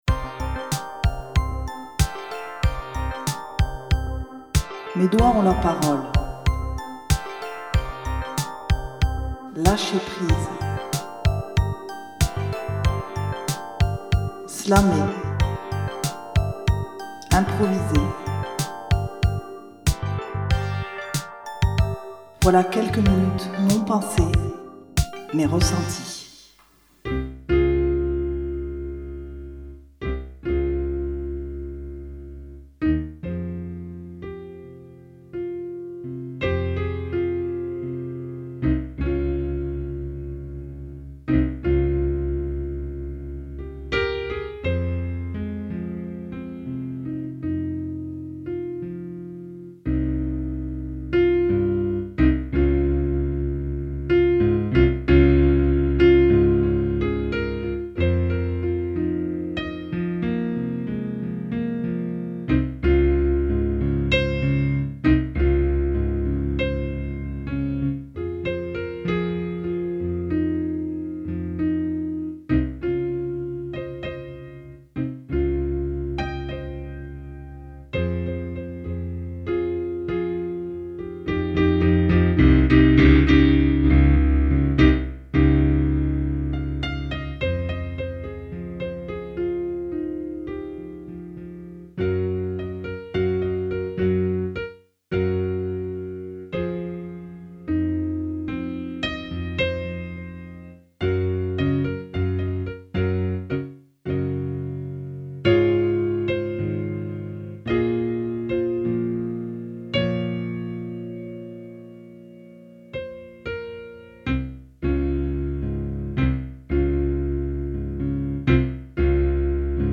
enregistré @ Radio Muge Studio.